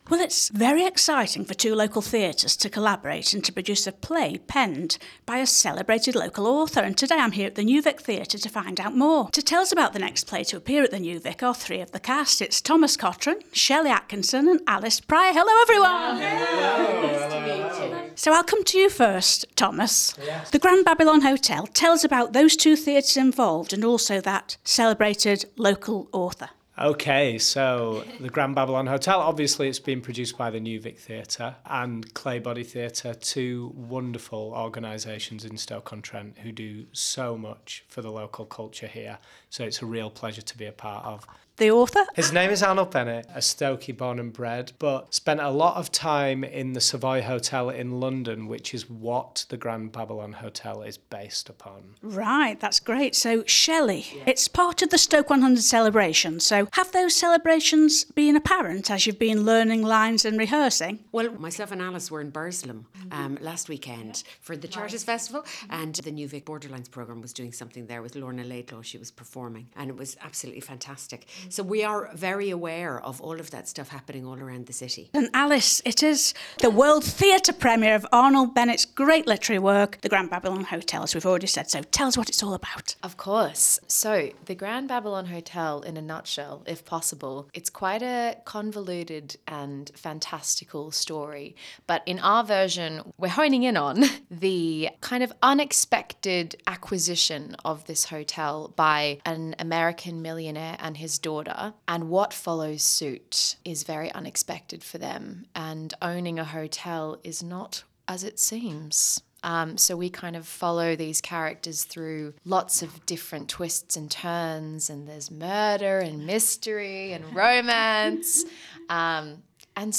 Hear what three of the cast